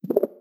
dialog-pop-in.wav